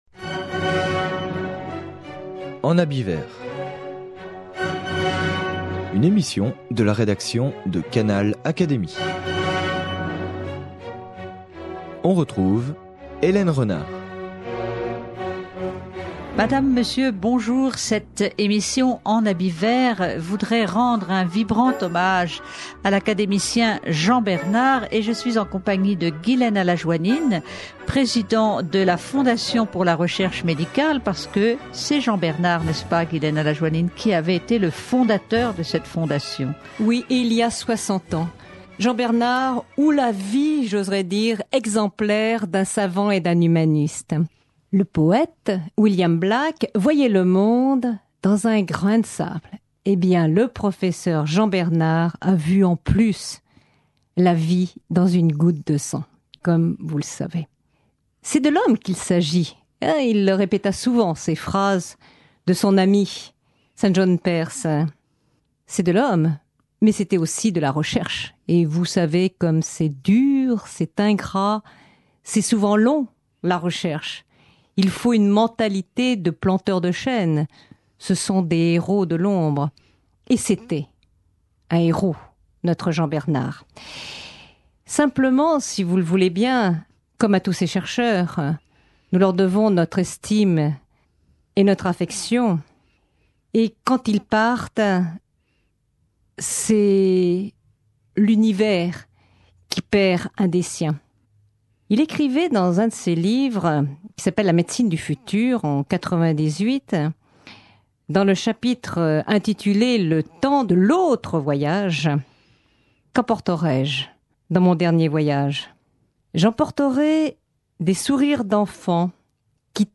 La cérémonie a eu lieu lors de la remise des prix de la Fondation, au Sénat, le 26 avril 2006.